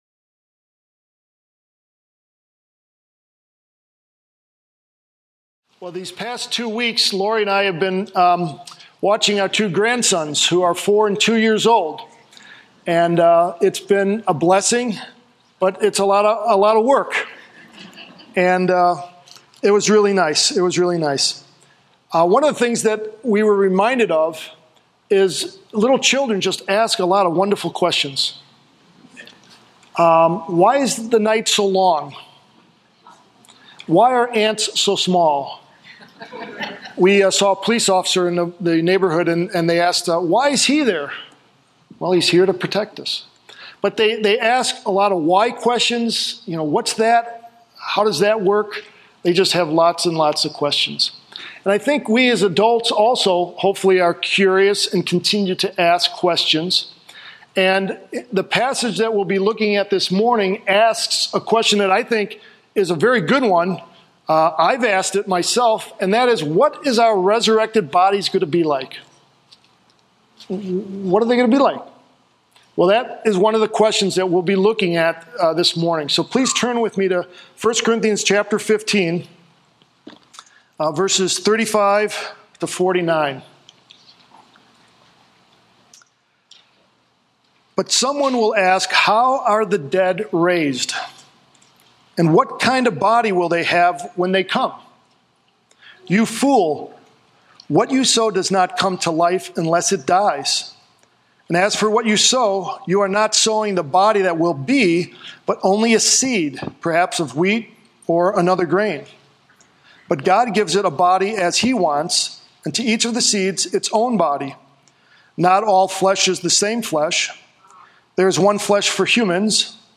An expositional preaching series through Paul's first letter to the church at Corinth.